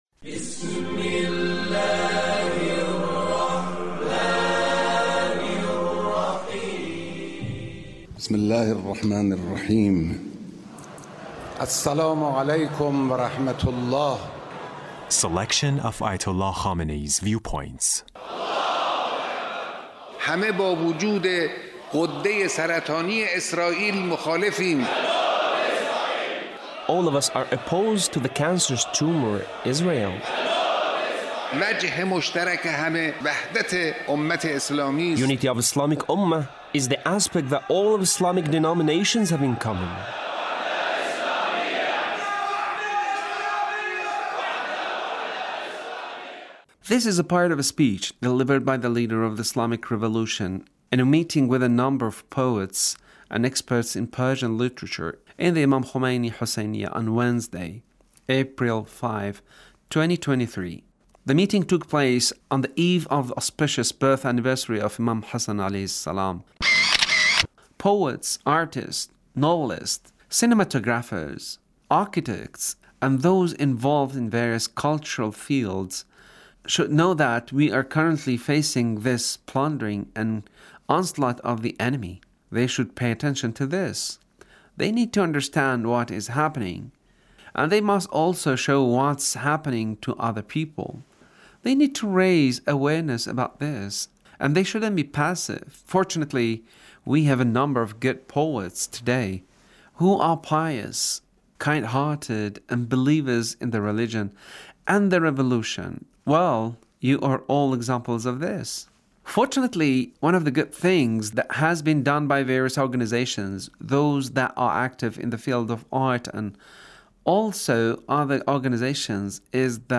Leader's Speech on Eid al Fitr